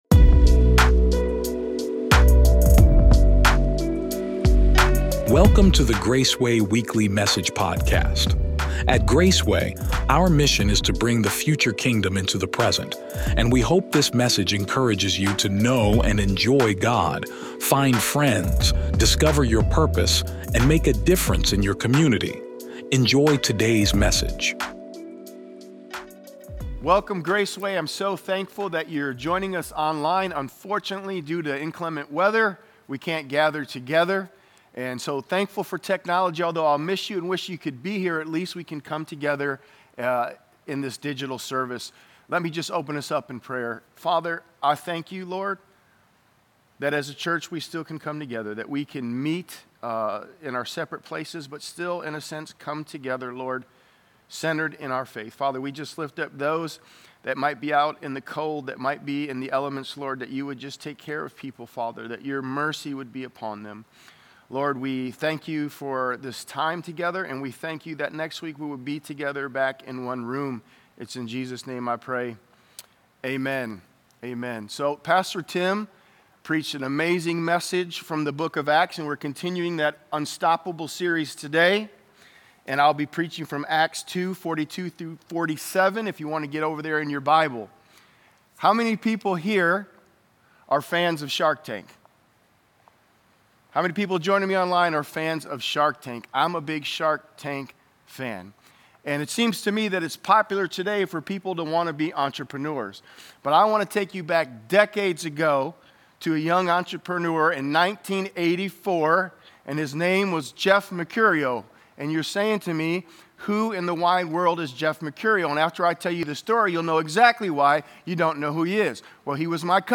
Weekend Messages